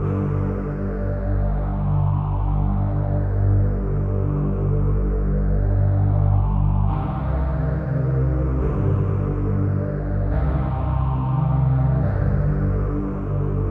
VTS1 Space Of Time Kit Melody & Synth